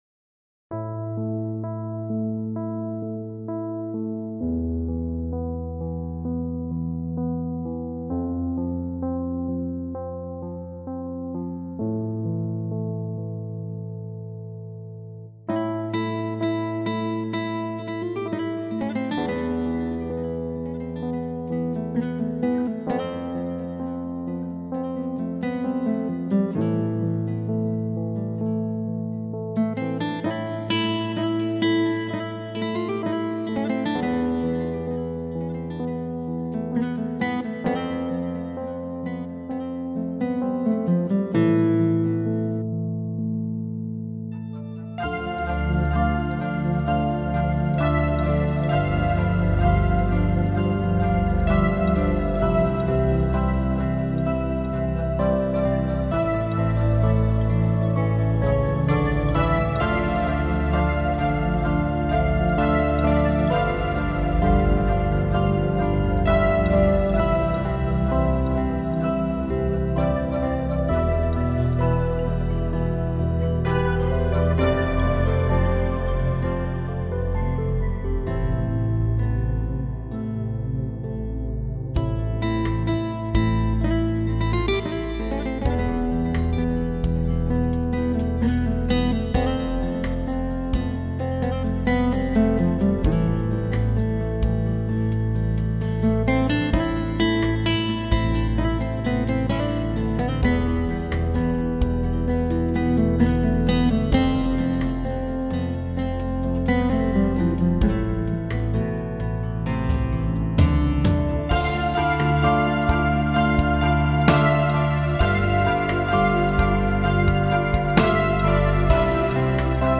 התחלתי לאחרונה לעבוד עם המידי באורגן.
ואגב - הכל סאונדים מהאורגן בלבד…